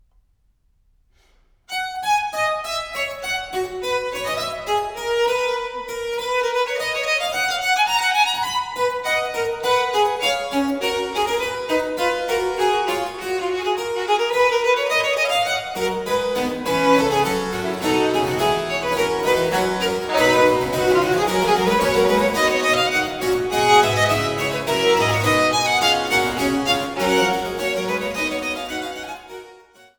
Die wohl bekanntesten Violinsonaten des 18. Jahrhunderts